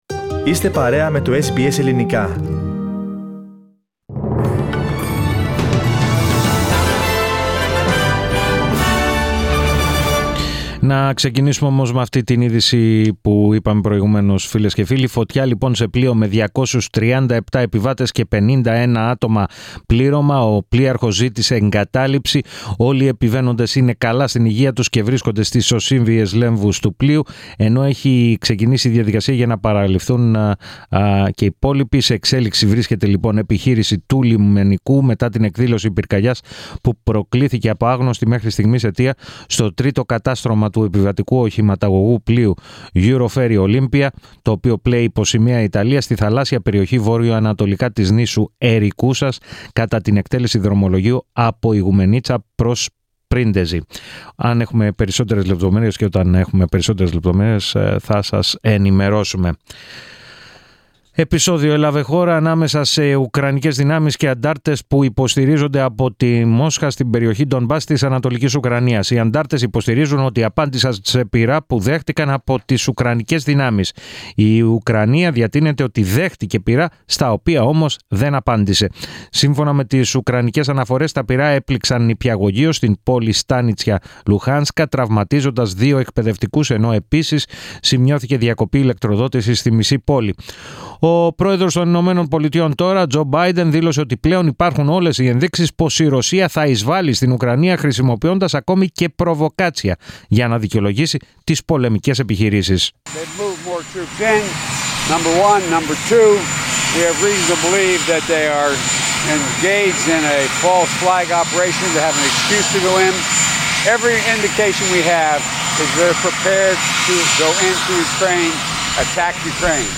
Listen to the main bulletin of the day from the Greek Program.